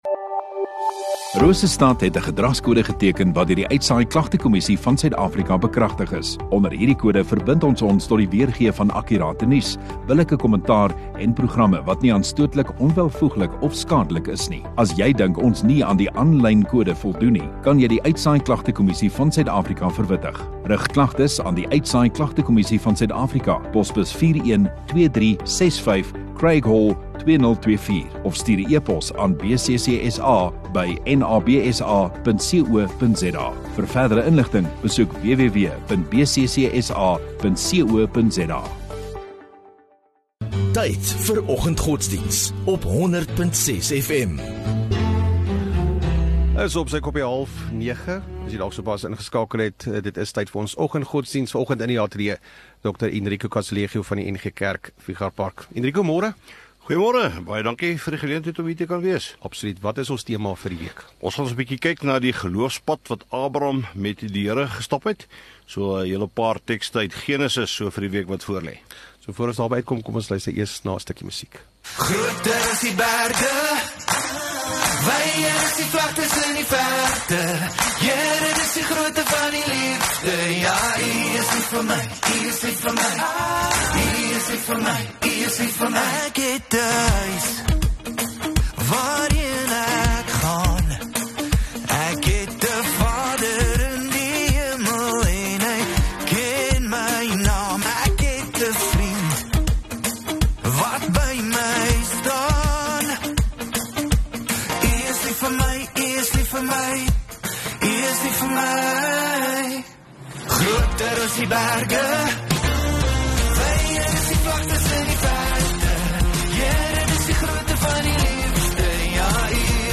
22 Jul Maandag Oggenddiens